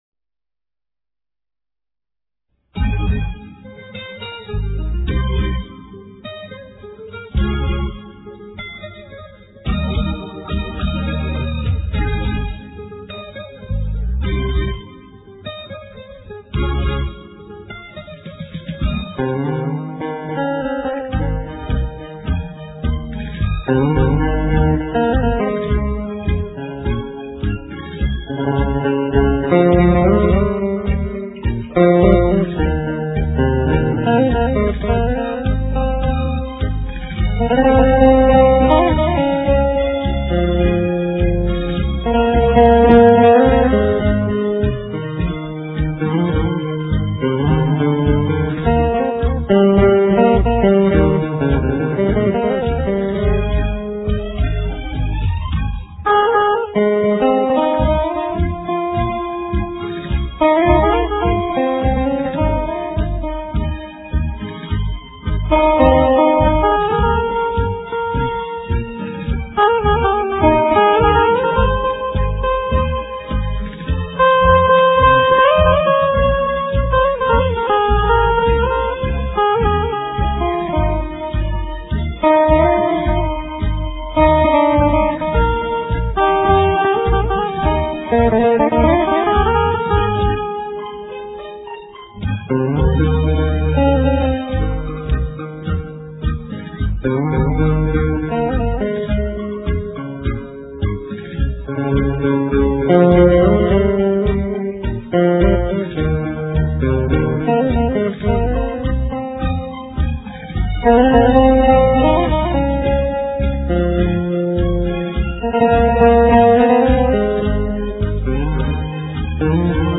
Ca sĩ: Không lời